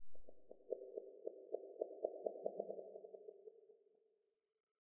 Minecraft Version Minecraft Version 1.21.5 Latest Release | Latest Snapshot 1.21.5 / assets / minecraft / sounds / block / creaking_heart / hurt / trail2.ogg Compare With Compare With Latest Release | Latest Snapshot